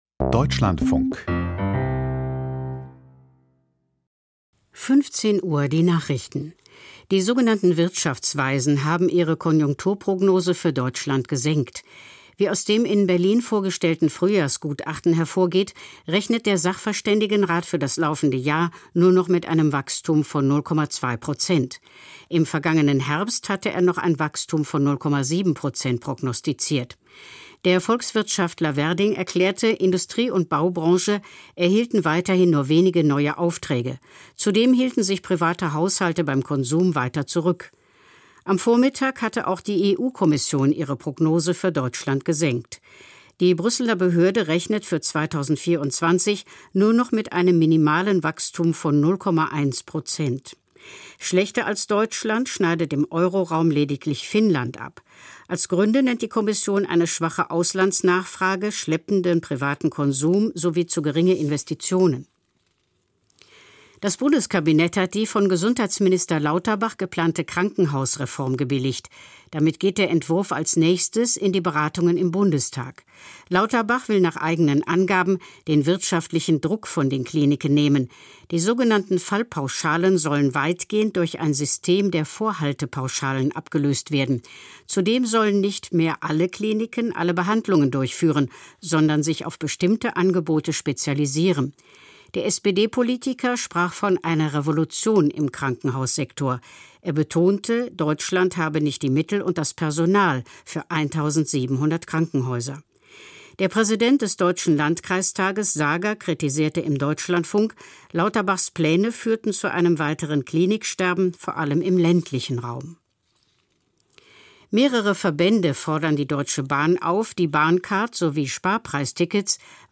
Kita-Träger in NRW warnen vor finanziellem Ruin, Interview